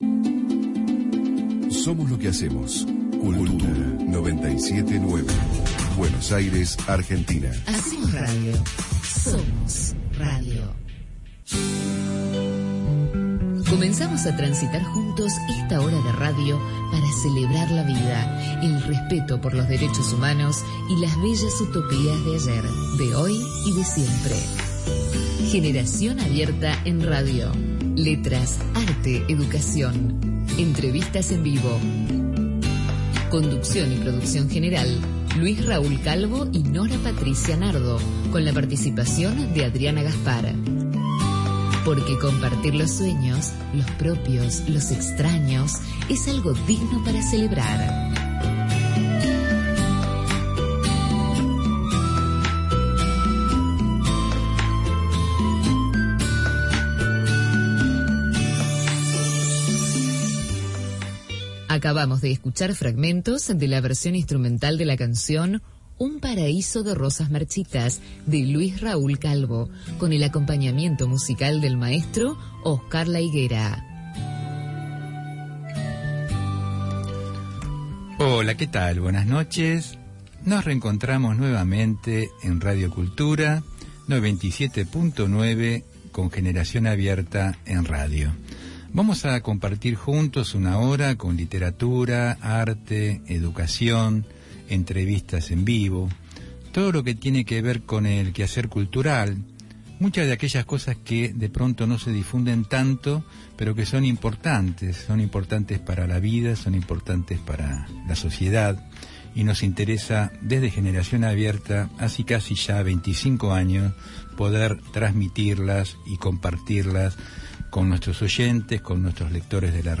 Por la Radio AM 1010 “Onda Latina” , Buenos Aires, Argentina.